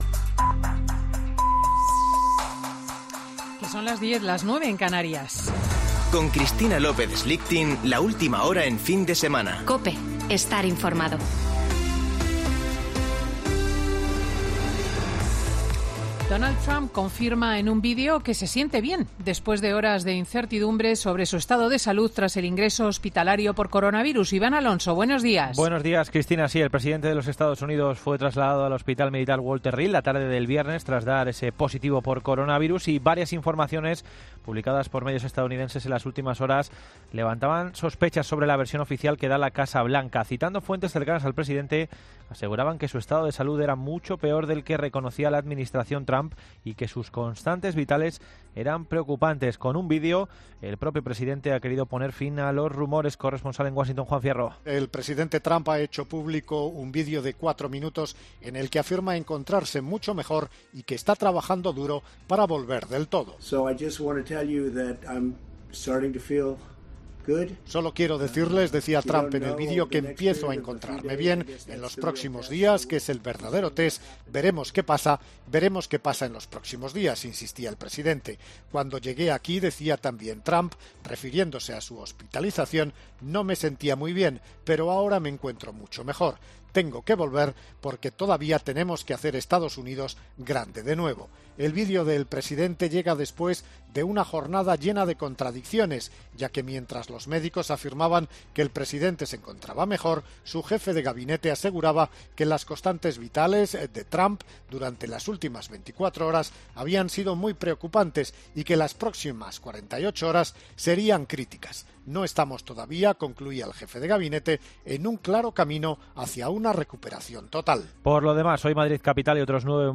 AUDIO: Ya puedes escuchar el monólogo de Cristina López Schlichting de este domingo 4 de octubre de 2020 en el programa 'Fin de Semana'